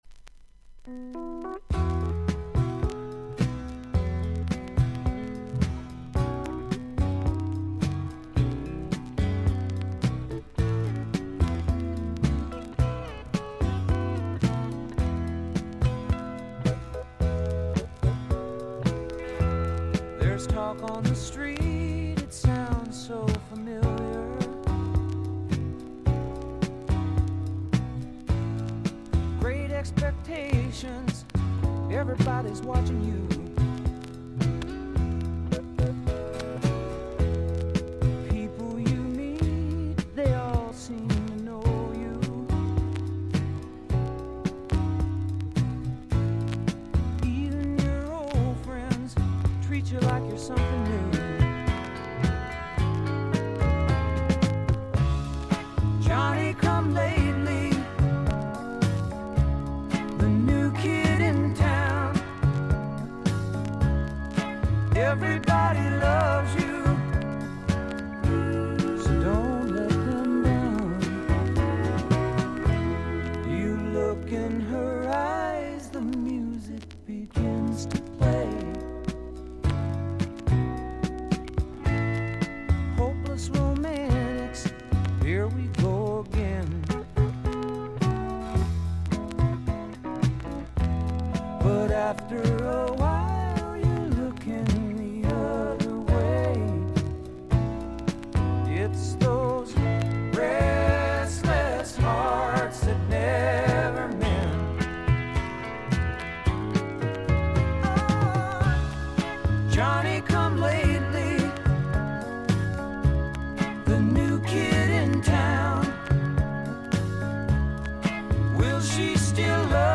A2中盤までノイズが多めにあります。